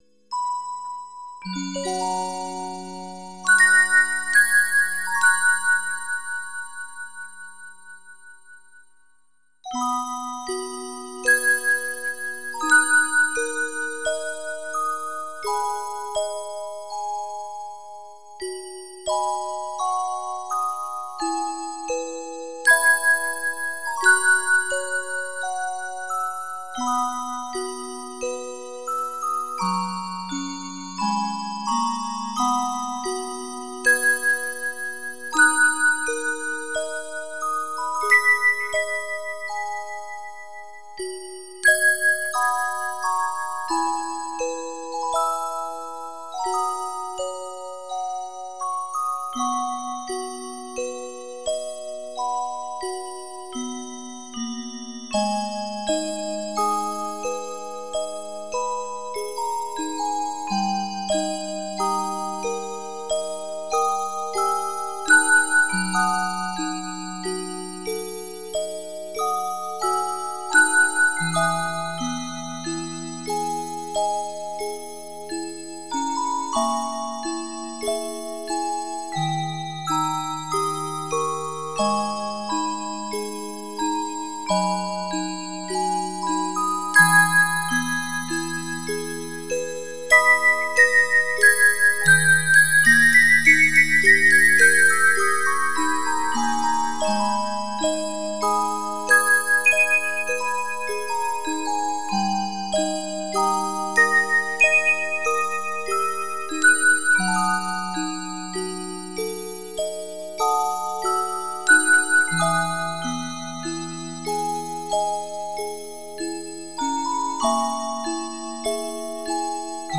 BGM